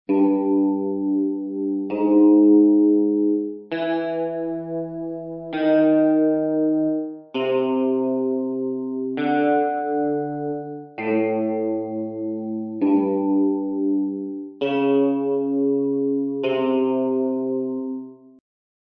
Ascoltiamo le note indicate nello spartito, facendo attenzione all'effetto delle alterazioni : alterazioni_01.mp3